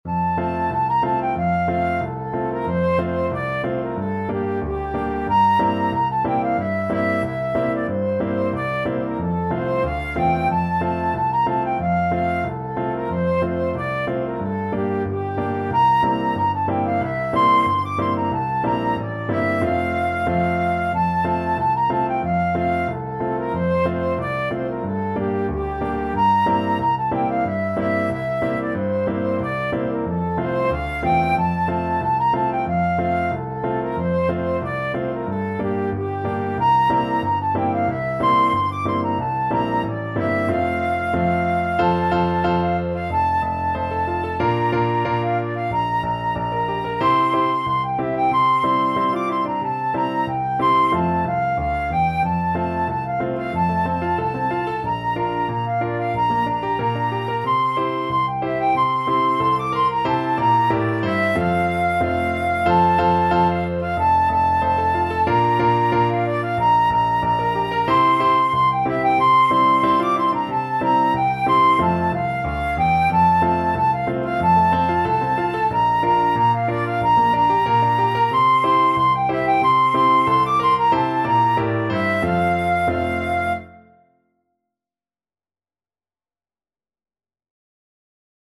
Traditional Music of unknown author.
2/4 (View more 2/4 Music)
Moderato =c.92